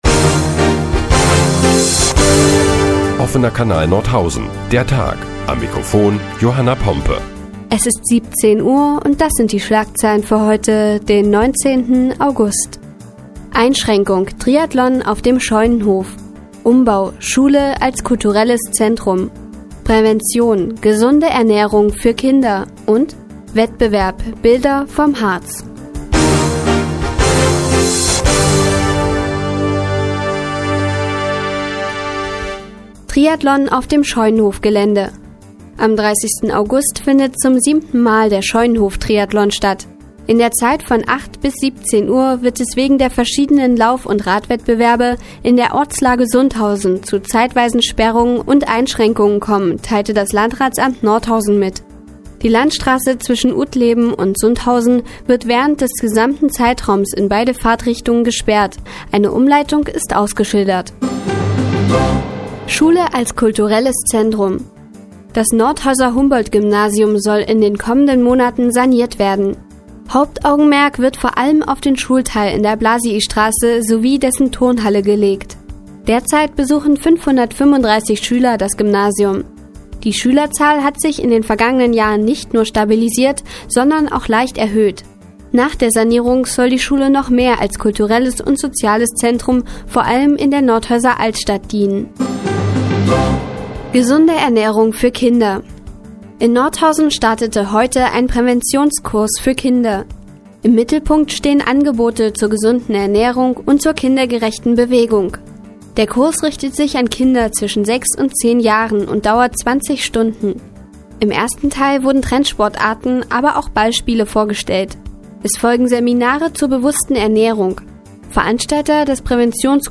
Die tägliche Nachrichtensendung des OKN ist nun auch in der nnz zu hören. Heute geht es unter anderem um den Triathlon auf dem Scheunenhof und gesunde Ernährung für Kinder.